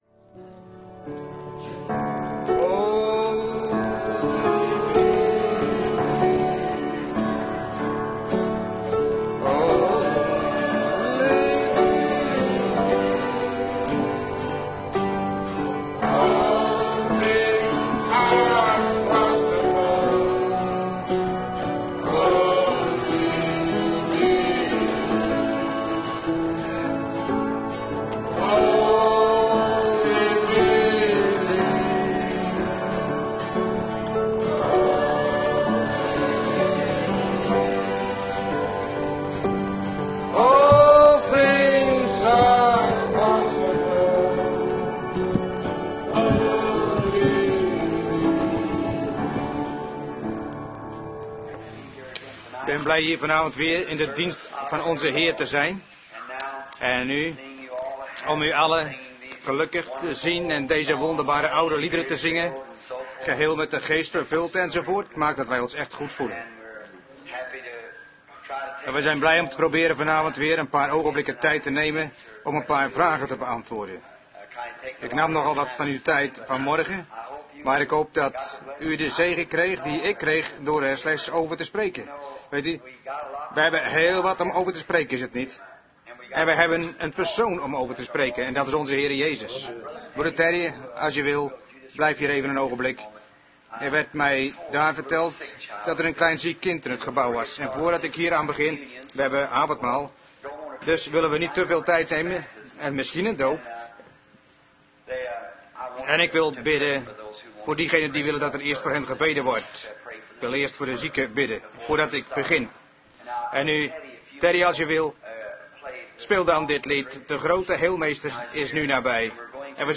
Prediking